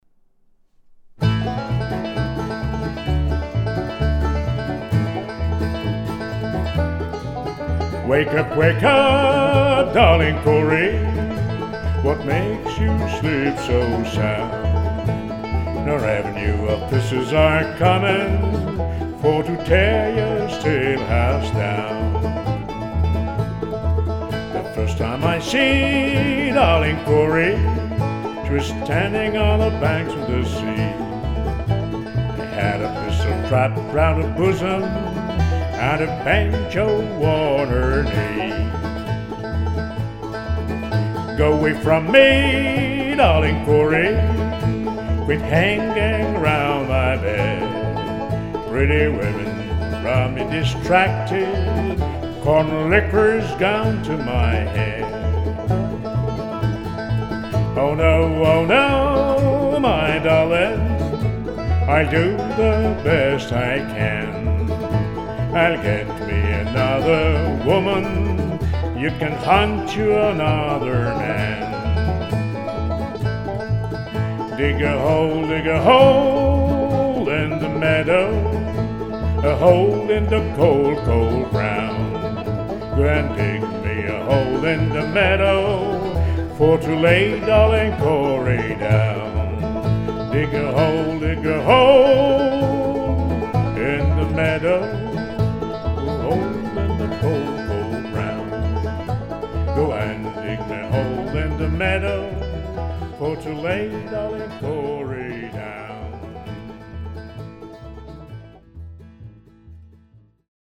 Chanson en anglais